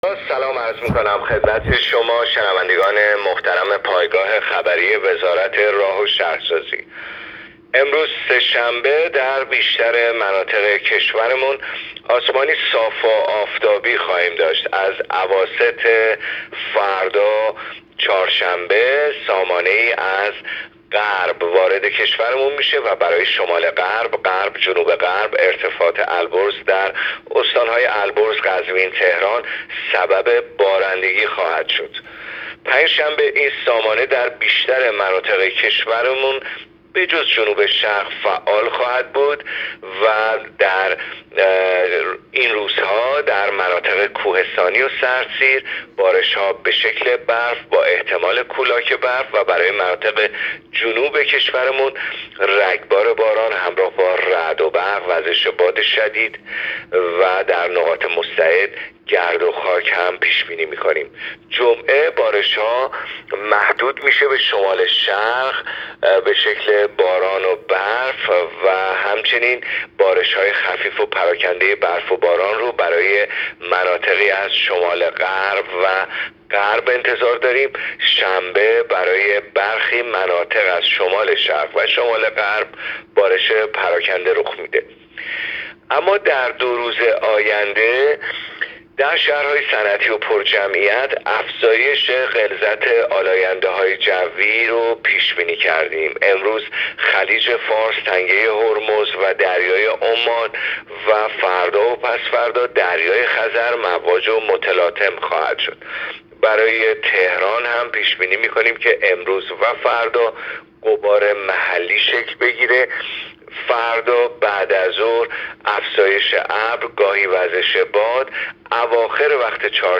گزارش رادیو اینترنتی از آخرین وضعیت آب و هوای هفتم بهمن؛